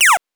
laser_shot_6.wav